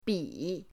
bi3.mp3